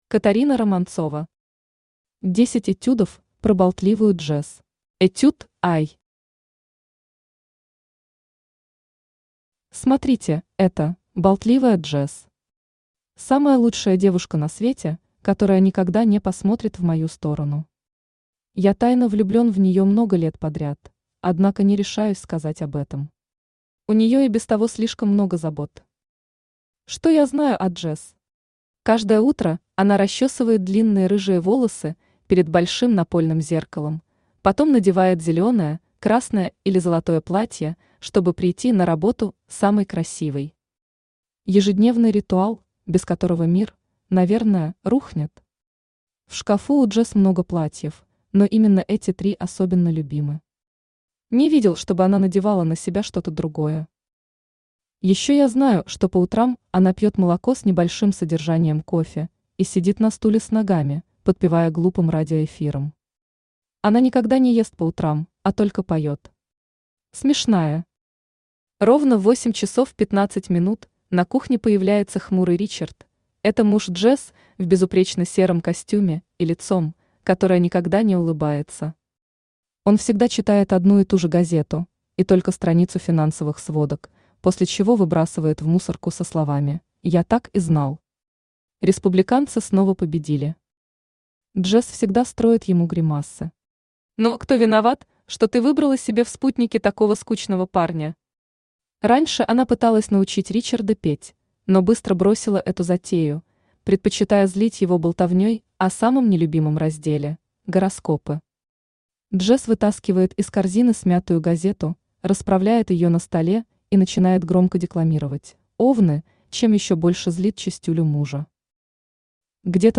Aудиокнига Десять этюдов про Болтливую Джесс Автор Катарина Романцова Читает аудиокнигу Авточтец ЛитРес.